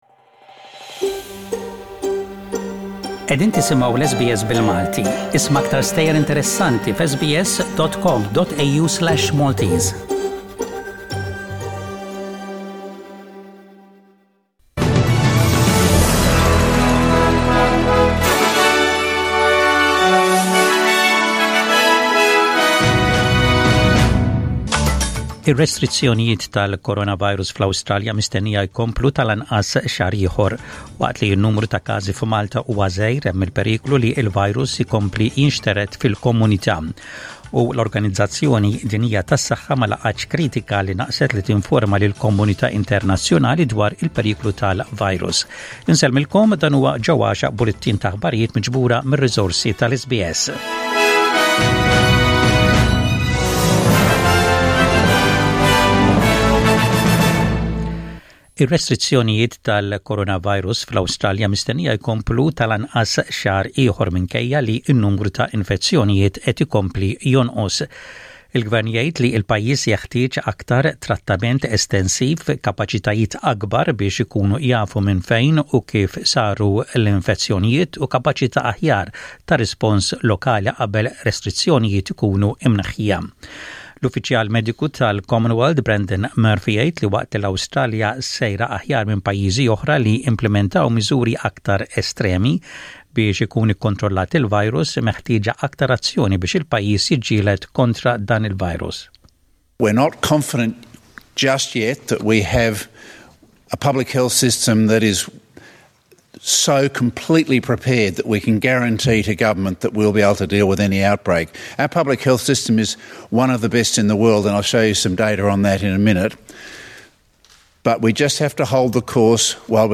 SBS Radio | Maltese News: 17/04/20